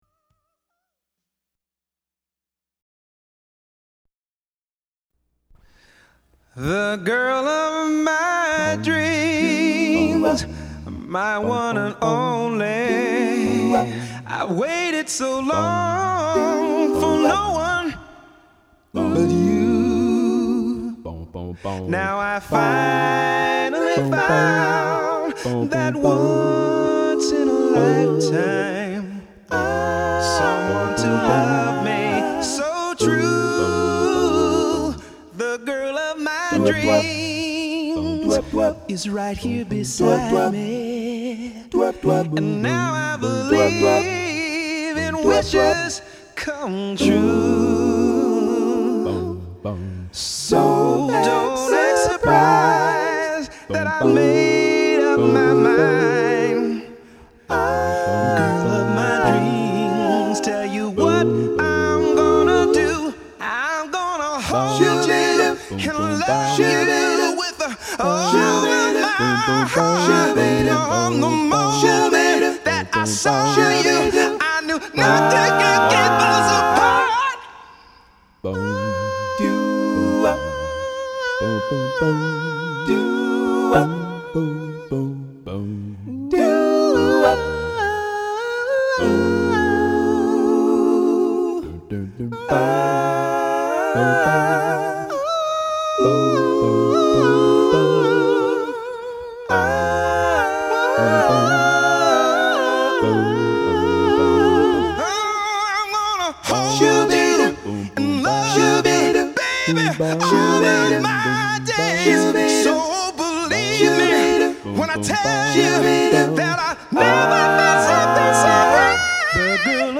Male1950sStylePop
Sweetersongs Male 1950's Style Pop
THEGIRLOFMYDREAMSDOOWOP.mp3